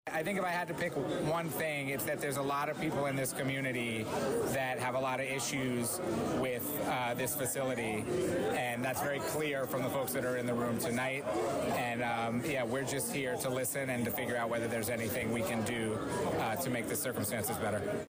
An overflow, standing room only crowd of several dozen filed into the Rabbittown Trade Center just off College Street in Danville Thursday night, as Hunterbrook Media hosted a community meeting concerning health and environmental issues involving Viscofan.